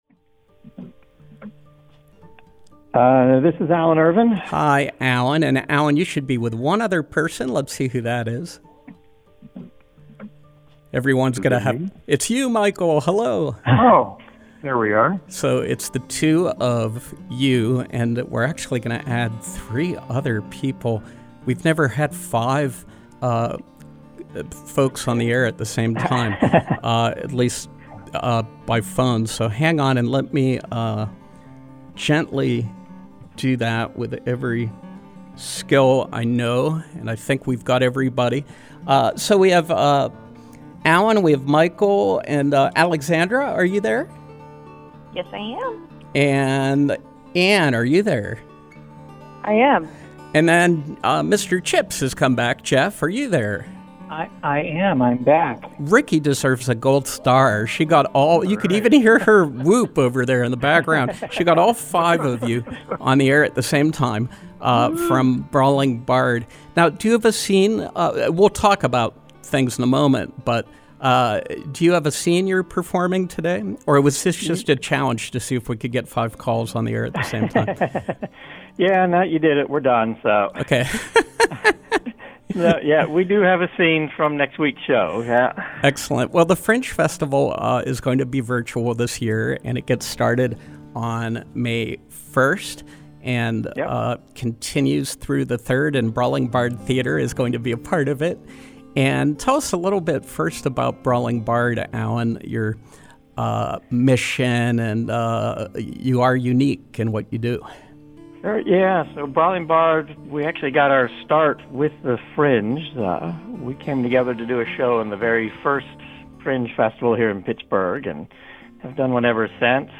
Interview: The Pittsburgh Fringe Festival, Brawling Bard Theater
From 04/25/2020: Brawling Bard Theater performers discussing plans to stream The Pittsburgh Fringe Festival, 5/1 to 5/3.